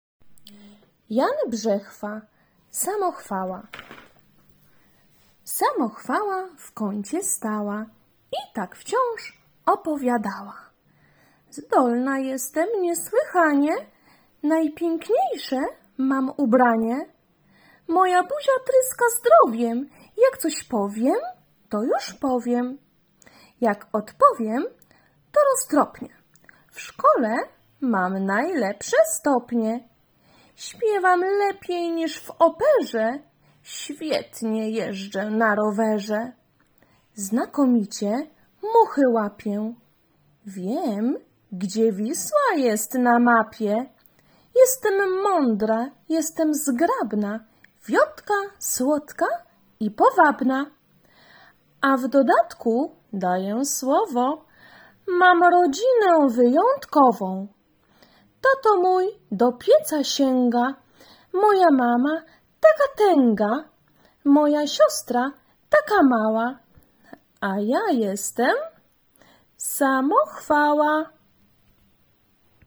Wiersze